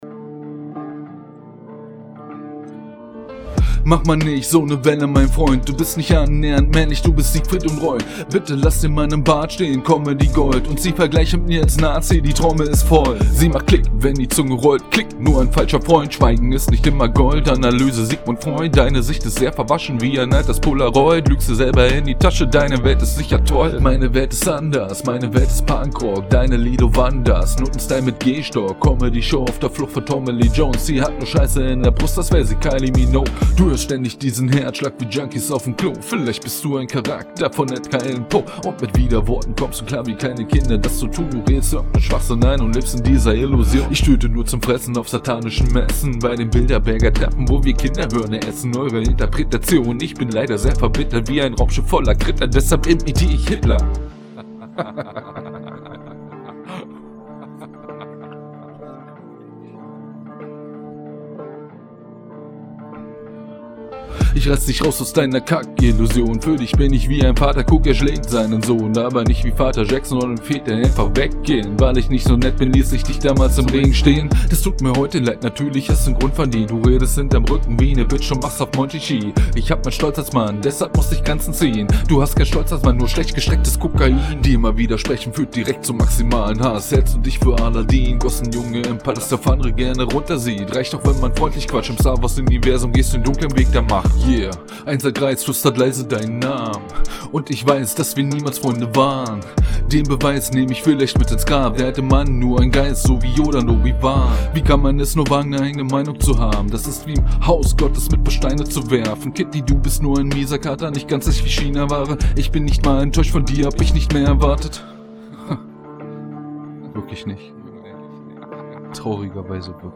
(schreibaufnahme)